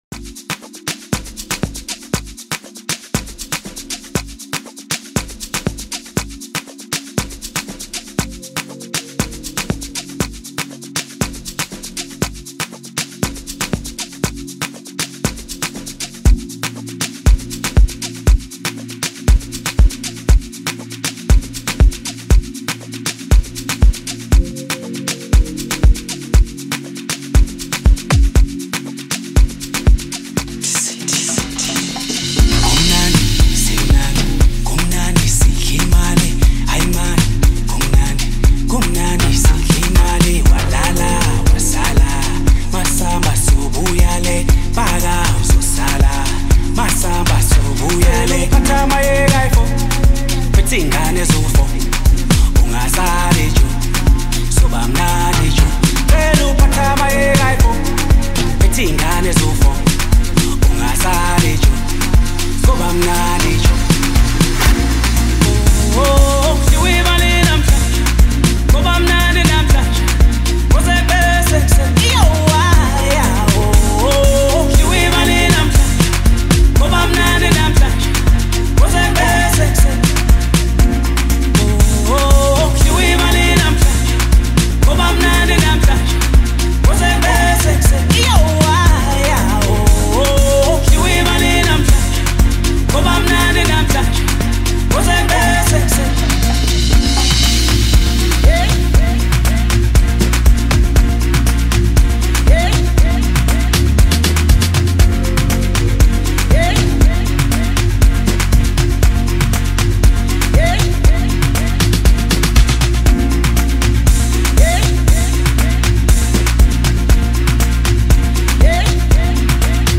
soulful voices